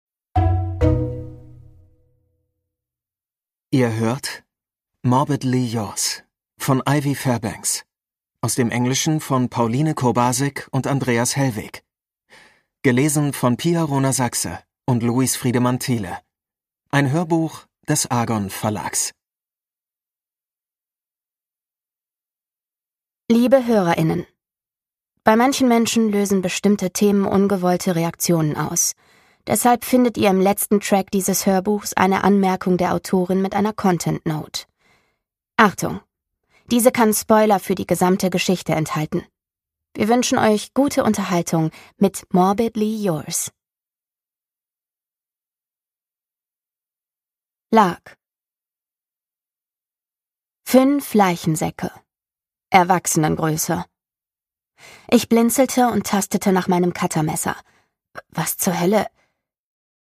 MP3 Hörbuch-Download